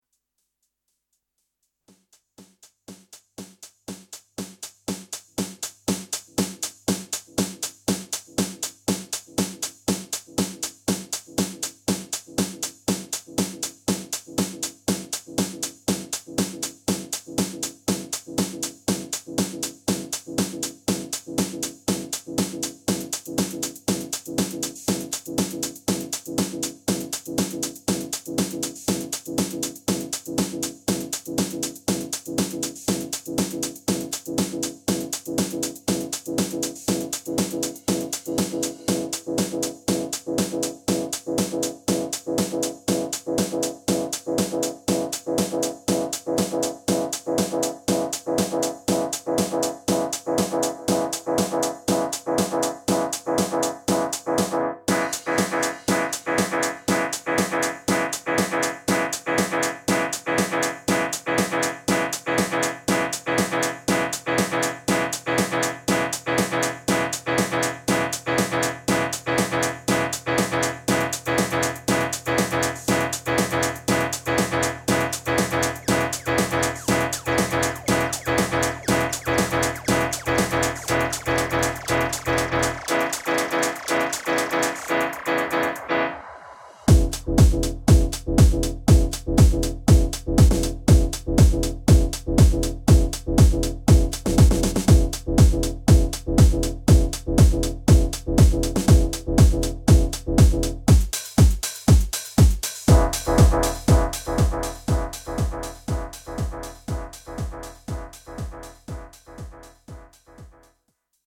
Nice versatile ep full of quality house tunes.